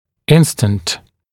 [‘ɪnstənt][‘инстэнт]мгновение, мгновенный, моментальный